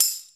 tambourine1.wav